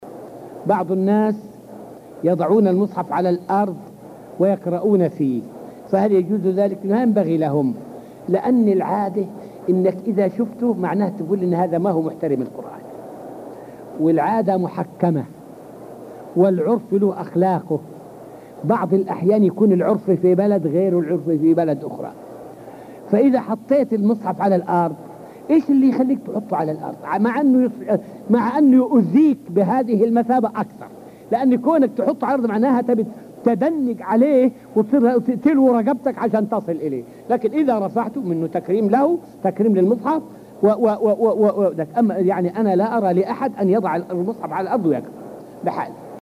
فائدة من الدرس الثاني من دروس تفسير سورة الرحمن والتي ألقيت في المسجد النبوي الشريف حول تقصير المسلمين بتلاوة كتاب الله تعالى ووزر من حفظ آية من كتابه تعالى ثم نسيها.